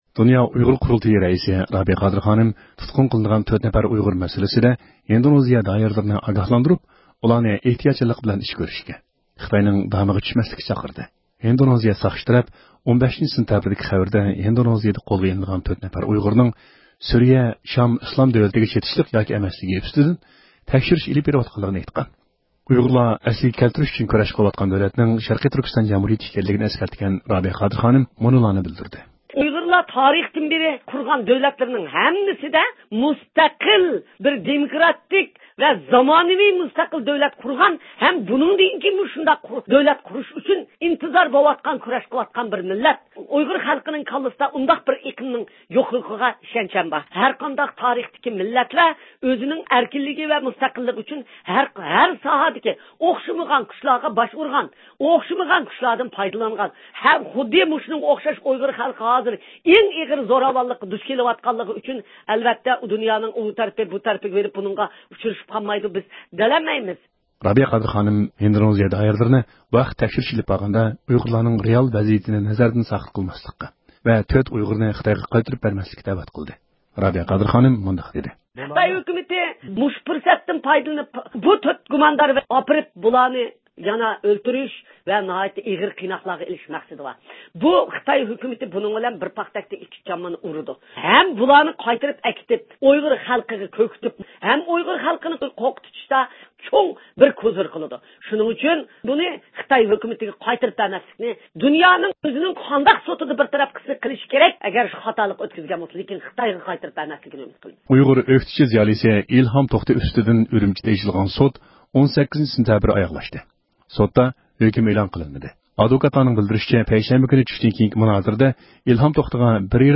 ھەپتىلىك خەۋەرلەر (13-سېنتەبىردىن 19-سېنتەبىرگىچە) – ئۇيغۇر مىللى ھەركىتى